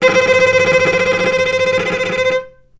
vc_trm-C5-mf.aif